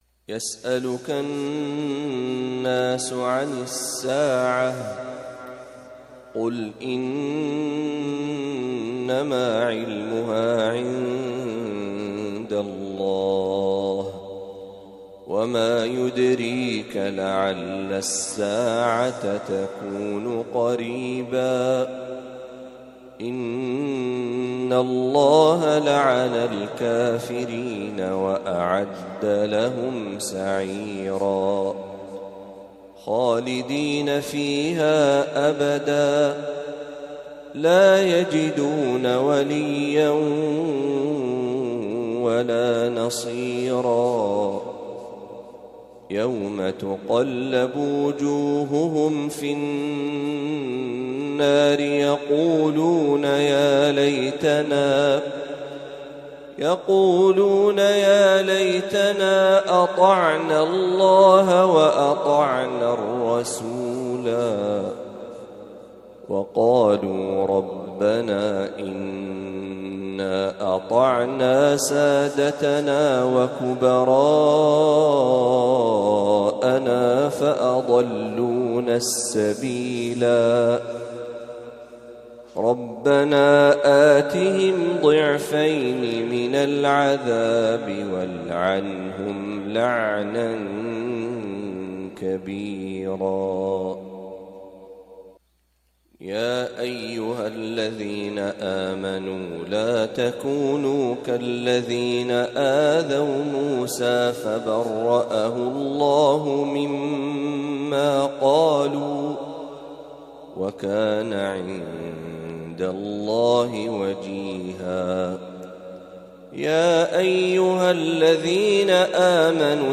خواتيم سورة الأحزاب | صلاة الجمعة